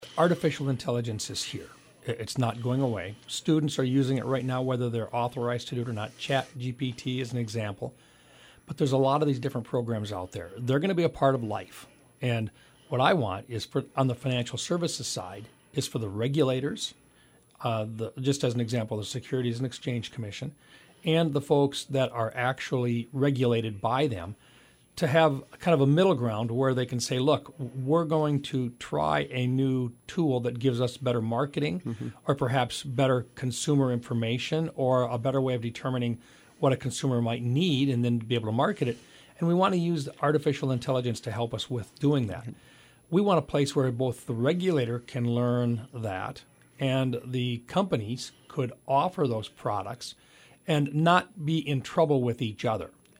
Recap of Senator Mike Rounds’ interview with Hub City Radio